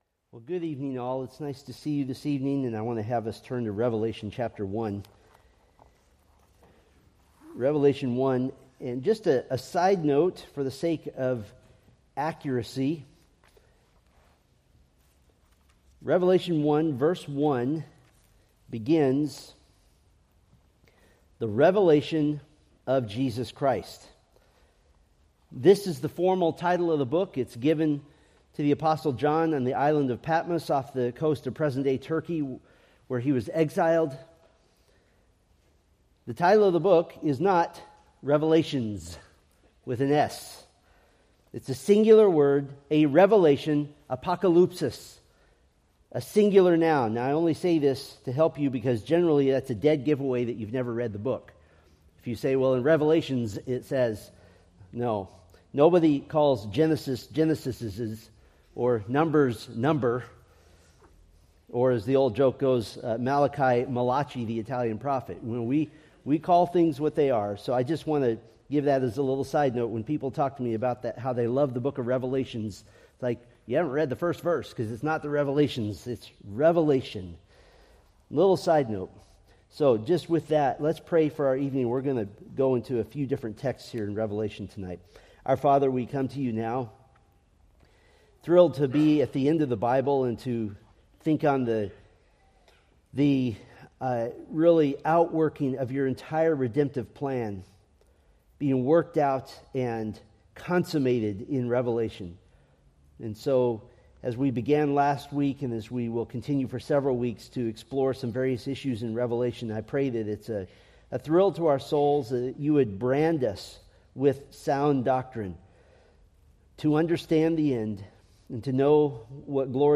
Preached January 19, 2025 from Selected Scriptures